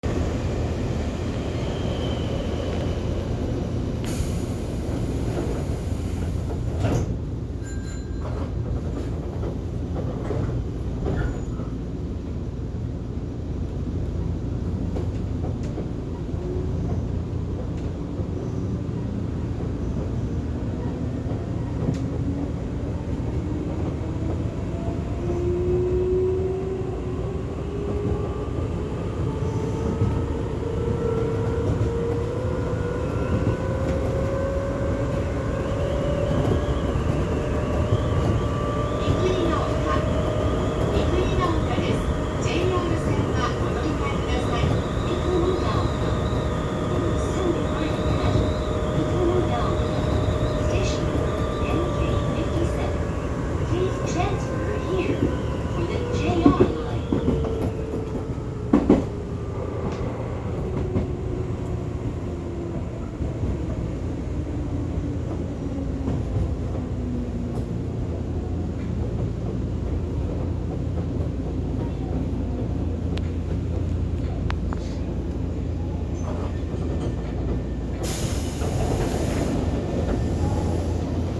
〜車両の音〜
・6300系走行音
【高野線】百舌鳥八幡→三国ヶ丘
ごく普通の抵抗制御となります。現在はタブレット式の自動放送が導入されています。